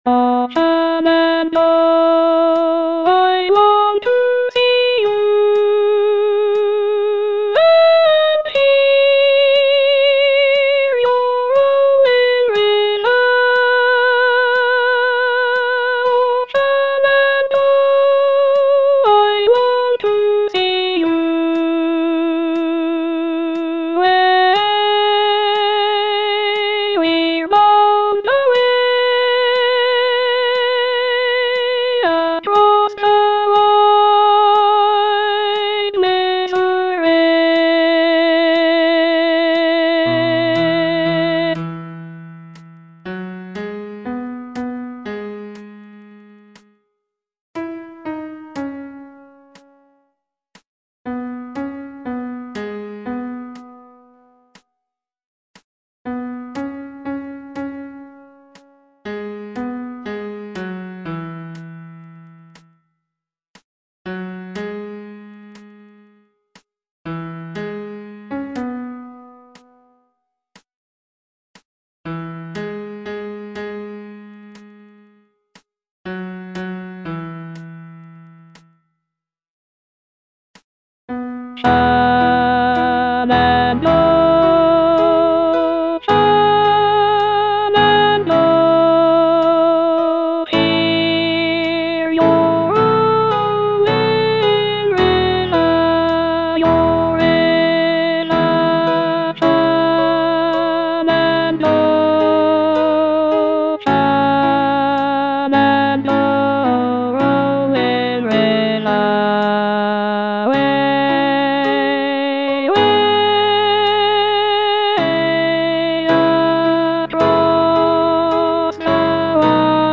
Alto II (Voice with metronome)